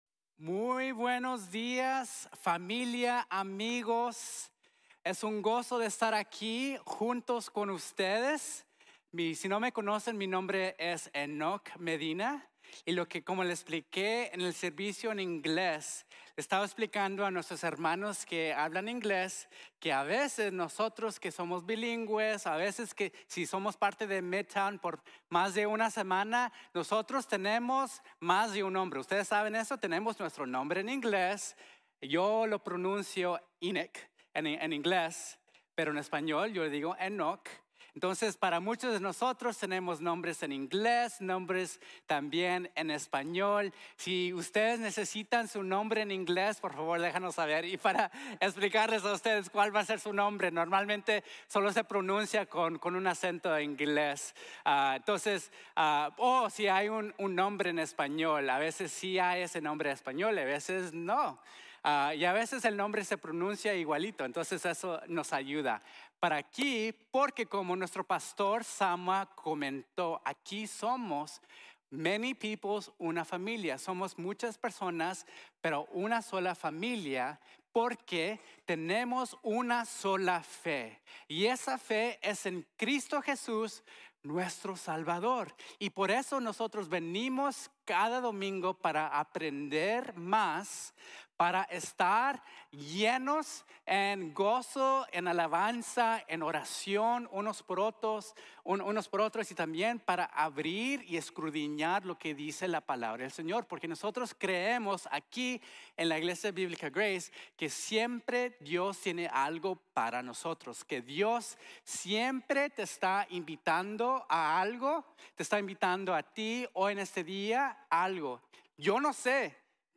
Ven a Nacer de Nuevo | Sermon | Grace Bible Church